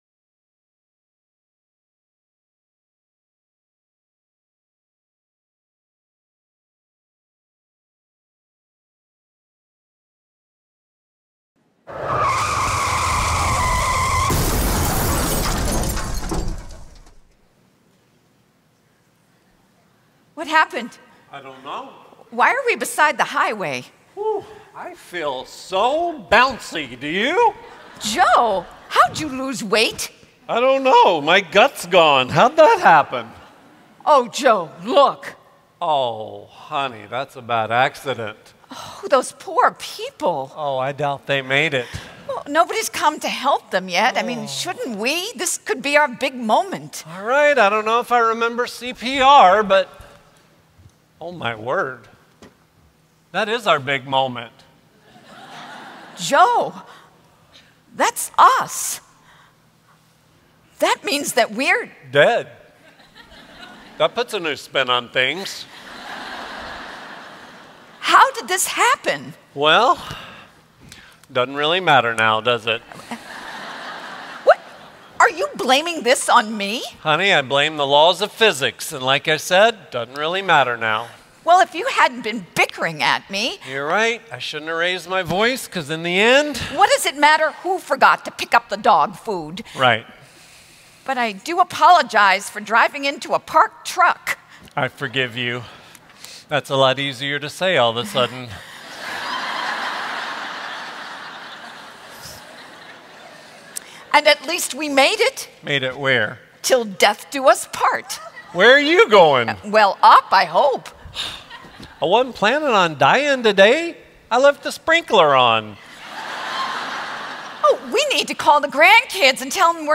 Message 11 (Drama): 'Til Death Do Us Part